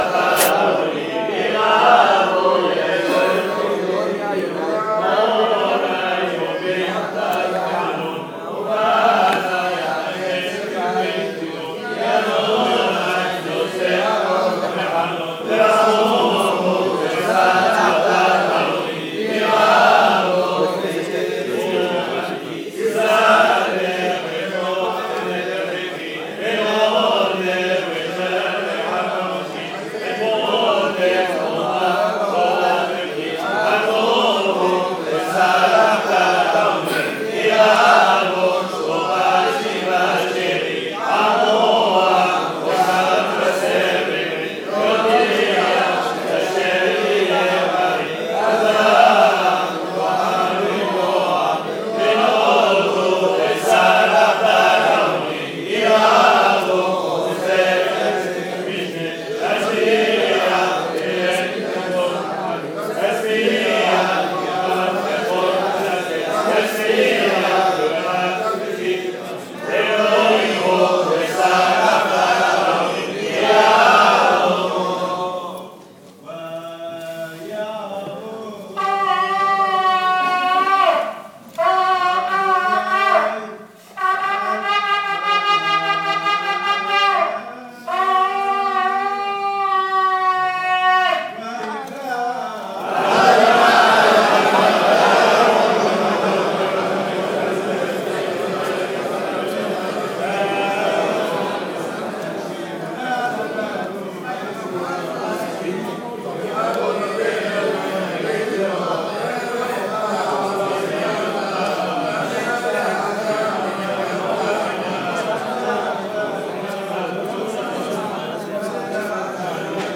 LECTURES DES SELIHOTES EN DIRECT .....
selihot_dimanche_matin_jeune1.mp3